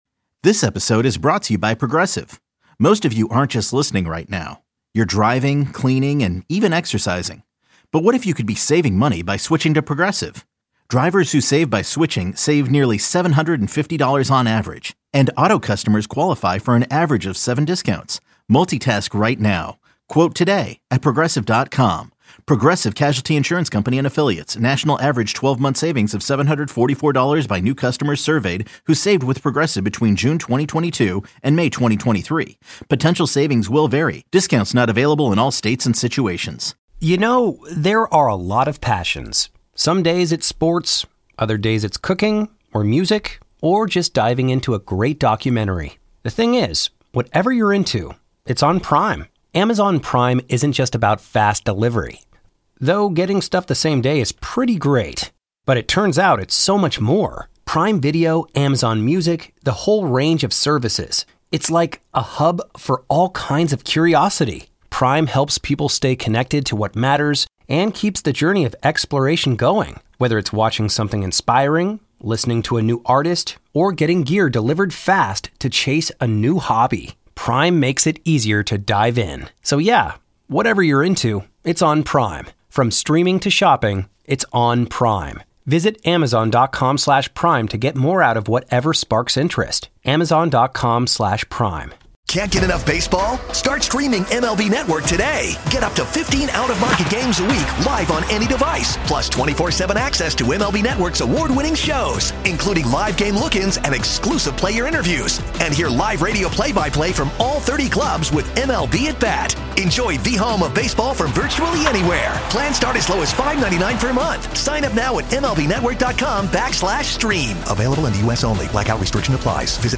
smart and compelling Chicago sports talk with great listener interaction. The show features discussion of the Bears, Blackhawks, Bulls, Cubs and White Sox as well as the biggest sports headlines beyond Chicago. Recurring guests include Bears linebacker T.J. Edwards, Pro Football Talk founder Mike Florio, Cubs outfielder Ian Happ and Cubs president of baseball operations Jed Hoyer.